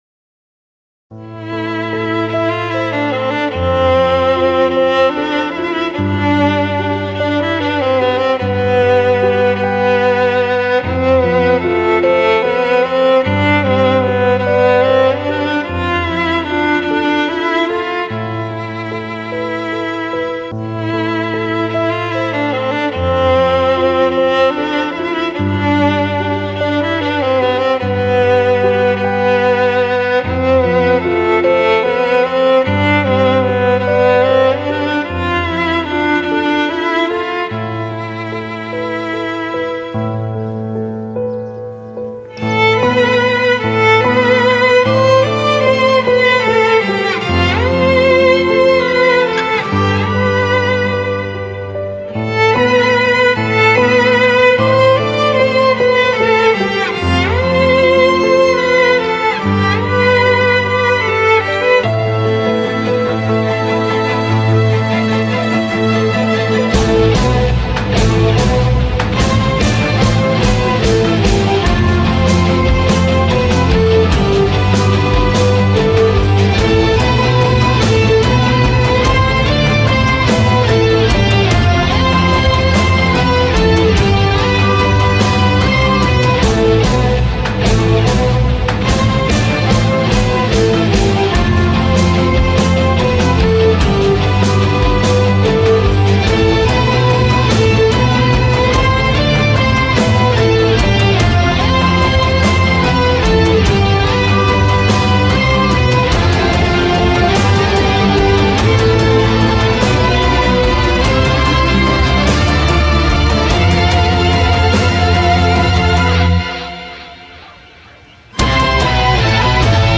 tema dizi müziği, duygusal hüzünlü rahatlatıcı fon müziği.